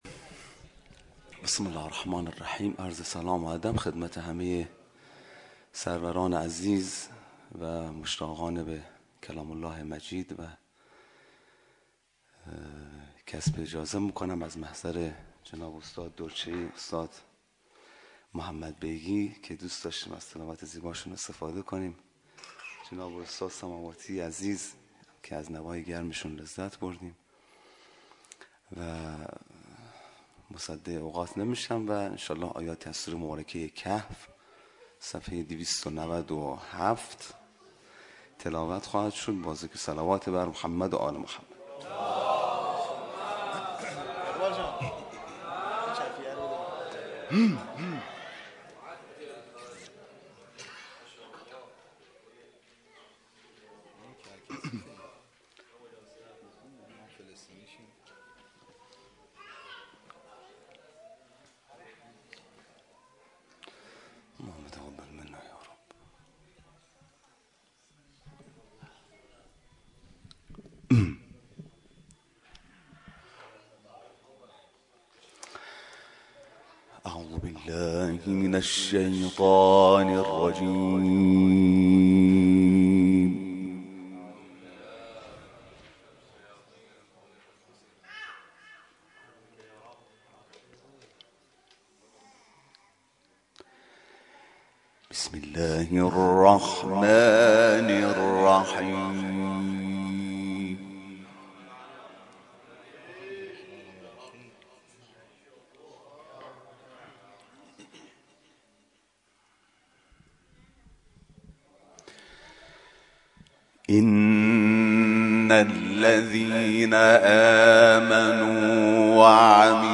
مجمع قاریان قرآن کریم شهرری با حضور و تلاوت قاری بین‌المللی کشورمان از مشهد مقدس همراه بود.